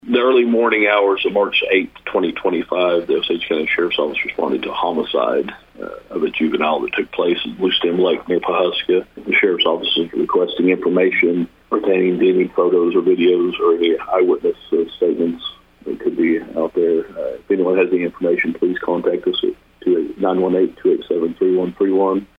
Sheriff Bart Perrier provides a preliminary investigation
Perrier on homicide Clip 1.mp3